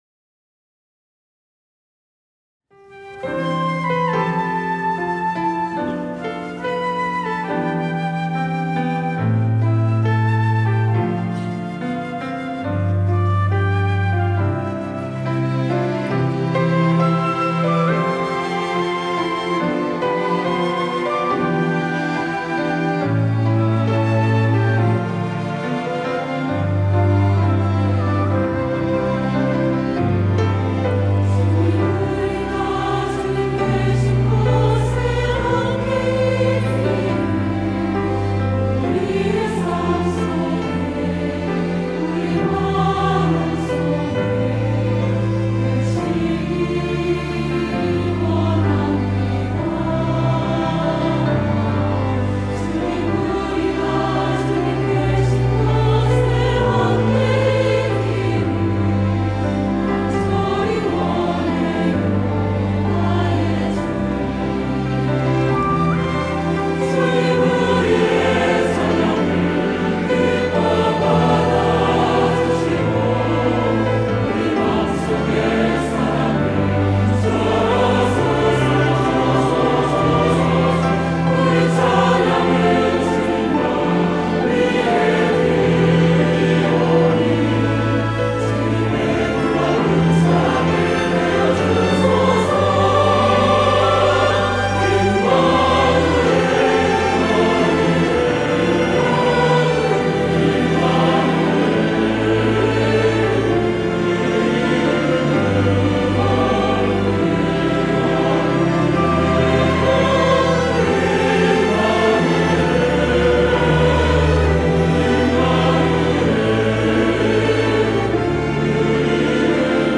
호산나(주일3부) - 주님과 함께
찬양대